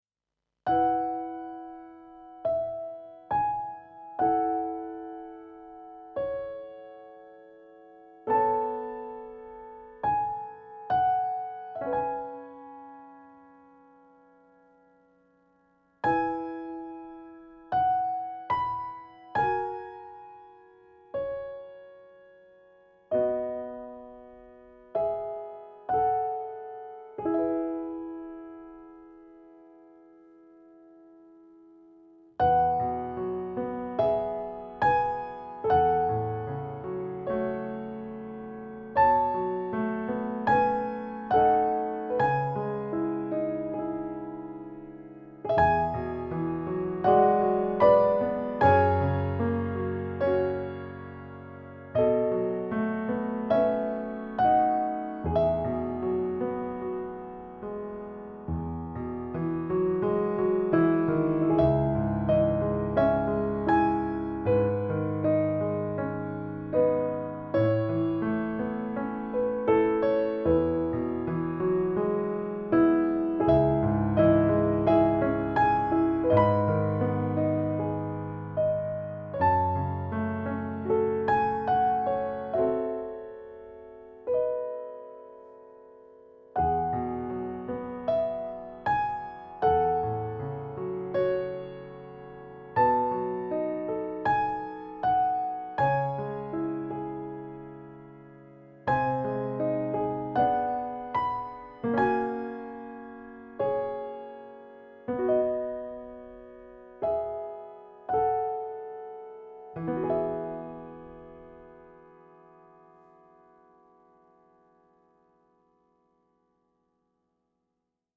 配乐试听